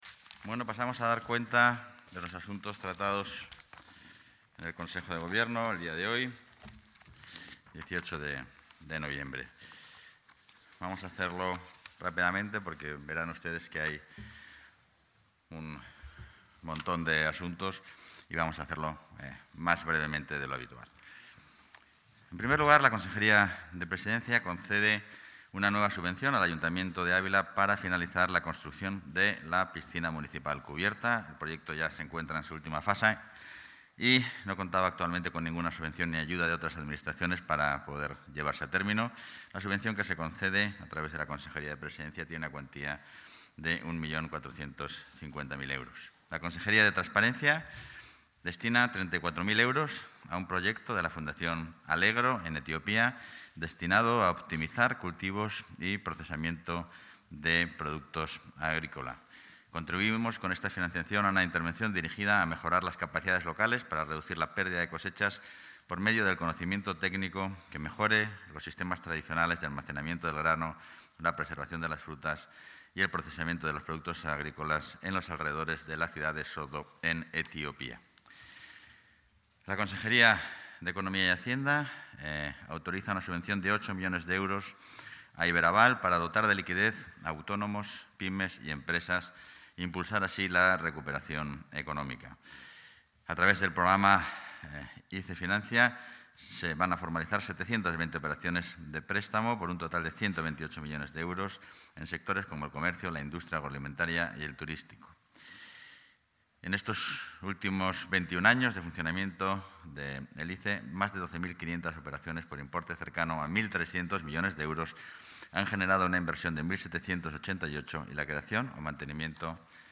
Intervención del vicepresidente de la Junta.